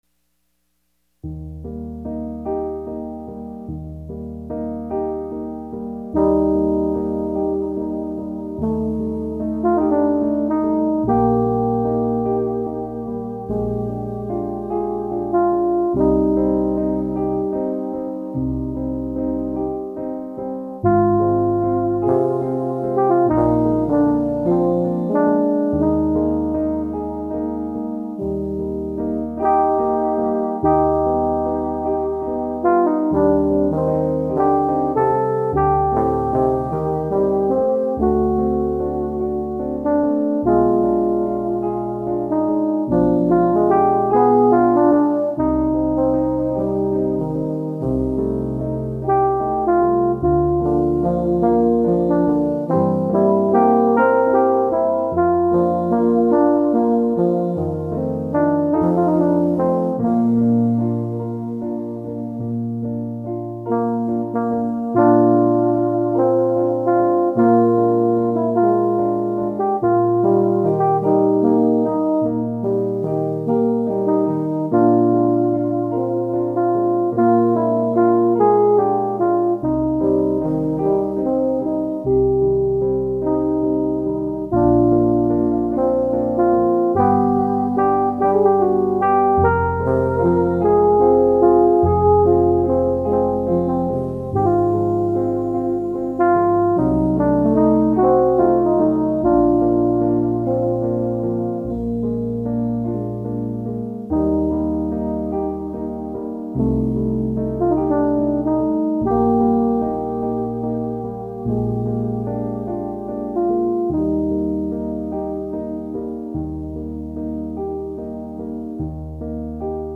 arranged for two tbns and piano